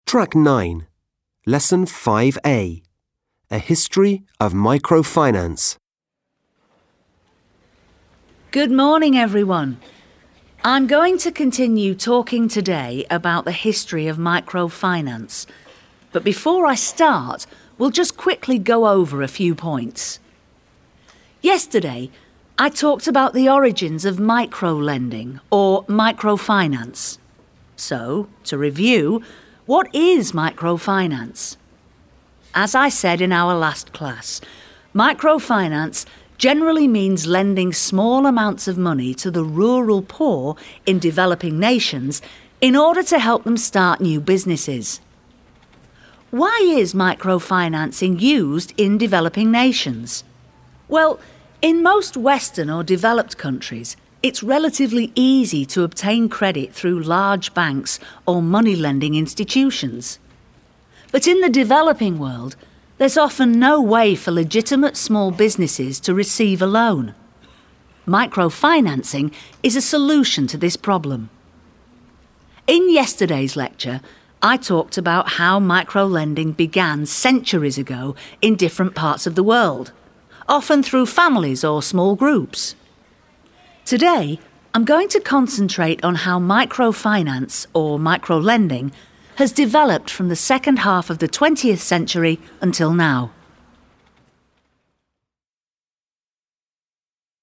4. Mokytojas supažindina mokinius su terminu microfinance ir pakviečia mokinius pasiklausyti įvado į paskaitą apie mikrofinansavimą. 4.